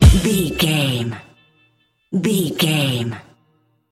Aeolian/Minor
drum machine
synthesiser
percussion
soul
confident
energetic
bouncy